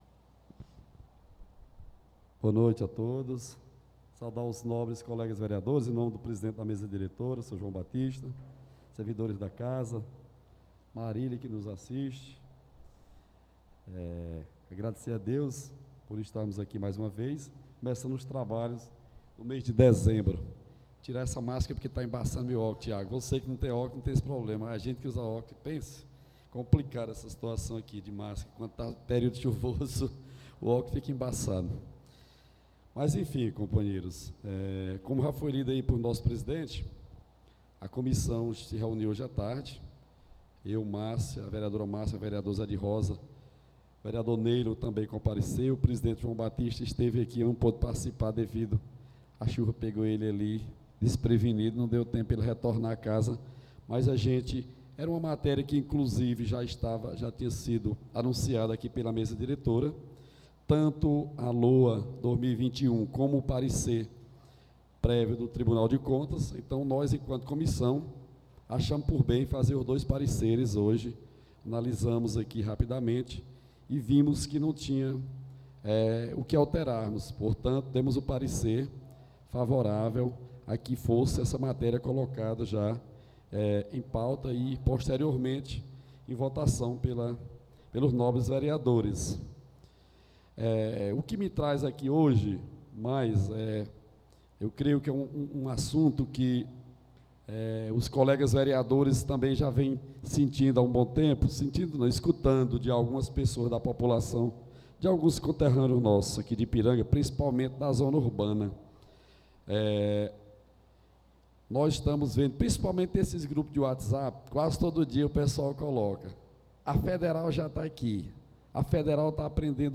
Pronunciamento Ver Edivaldo Fontes